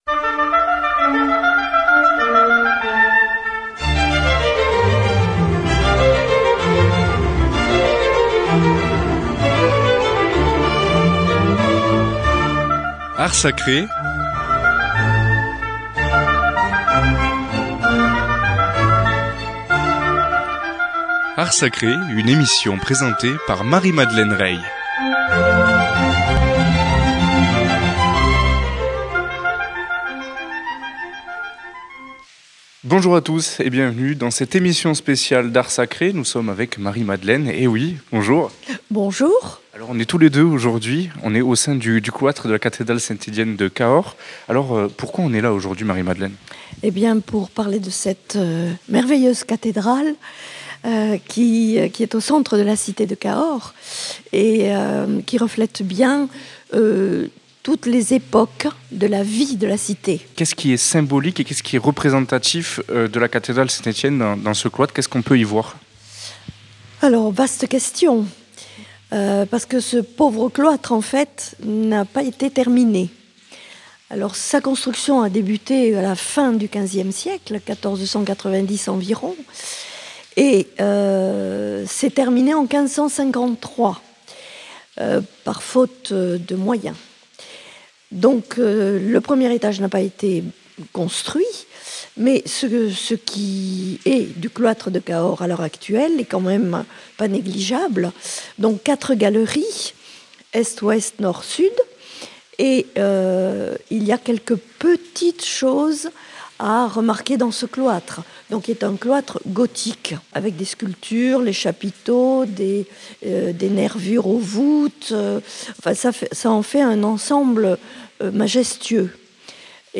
Visite guidée de la chapelle Saint Gausbert, située dans la galerie Est du cloître de la cathédrale Saint Etienne de Cahors.